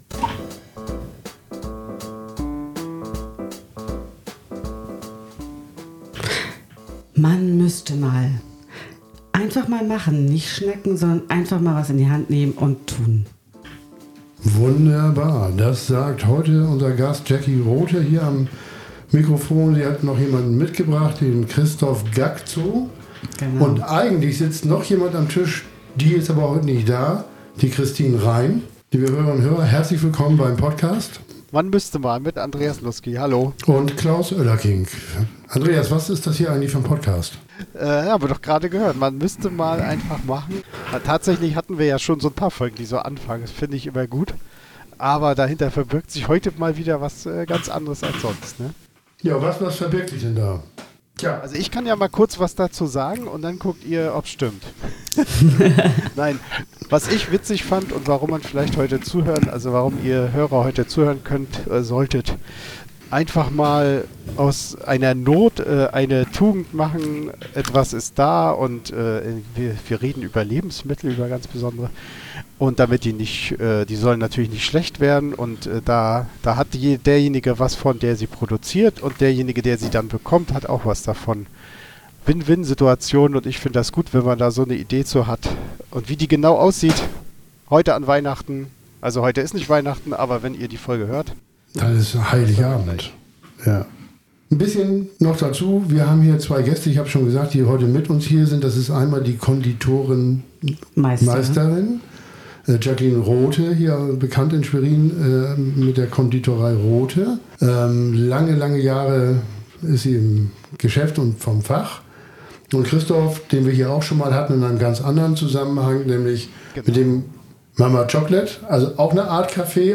Diese Folge haben wir nicht an Weihnachten aber zumindest am Nikolaustag, am 6. Dezember 2023 aufgenommen.